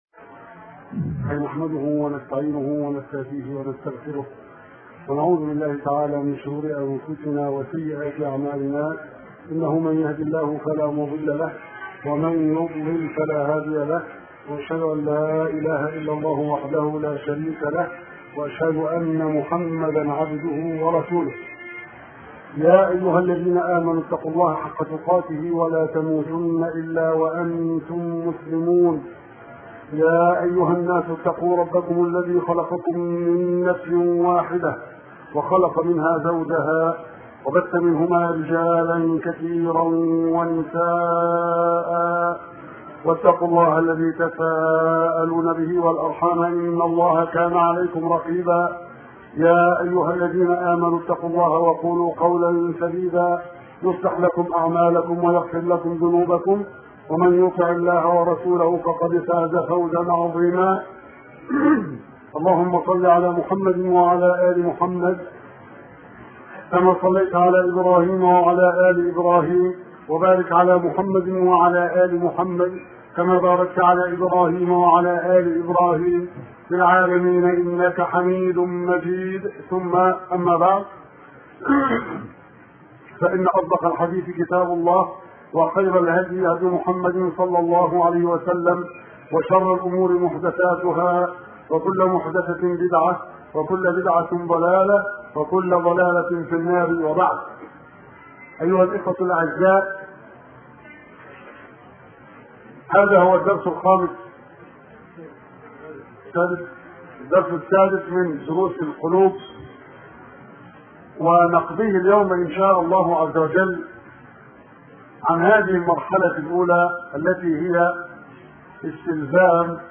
أرشيف صوتي لدروس وخطب ومحاضرات